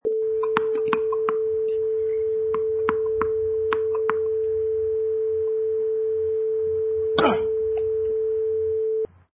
Sound effects: Tennis Serve with Male Grunt 1
Ball bounces then strong tennis serve as male player grunts
Product Info: 48k 24bit Stereo
Category: Sports / Tennis
Try preview above (pink tone added for copyright).
Tennis_Serve_with_Male_Grunt_1.mp3